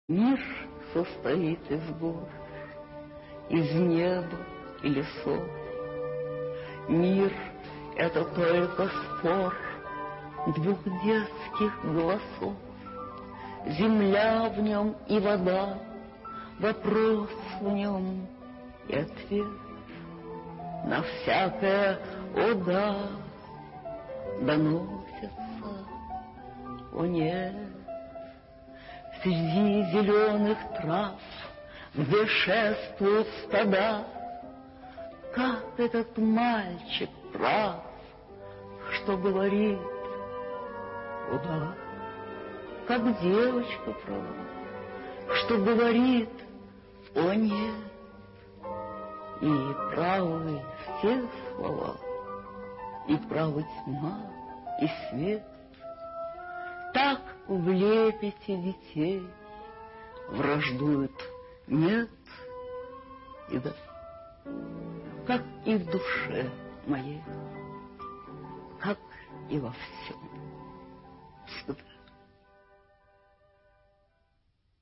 1. «Белла Ахмадулина – Мир состоит из гор… (читает автор)» /